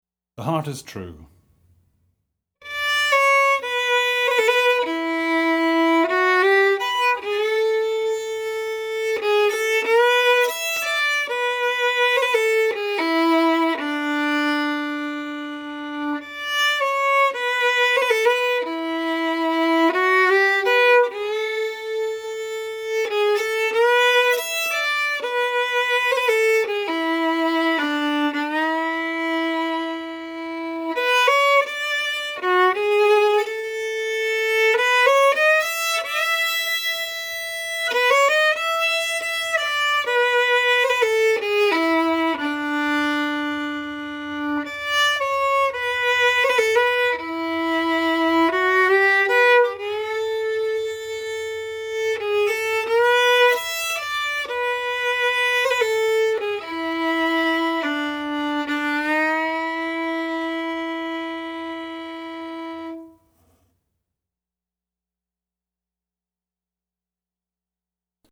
DIGITAL SHEET MUSIC - FIDDLE SOLO
Fiddle Solo, Celtic/Irish, Air